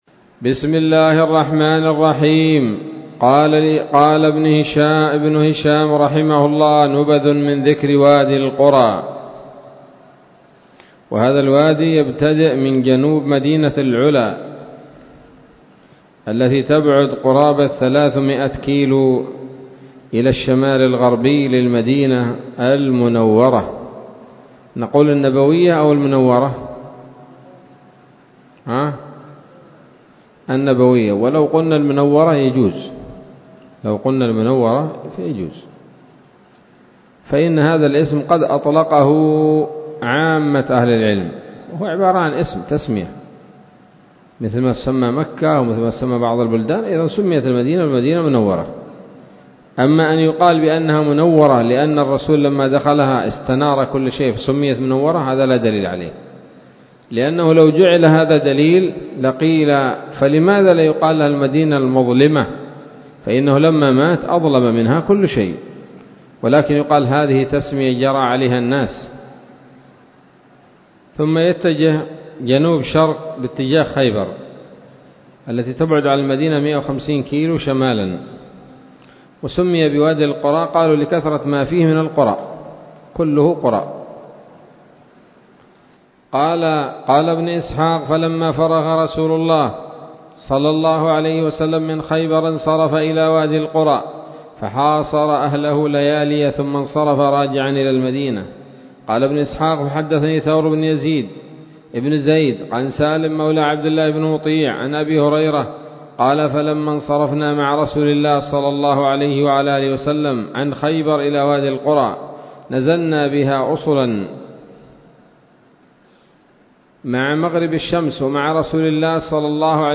الدرس الرابع والأربعون بعد المائتين من التعليق على كتاب السيرة النبوية لابن هشام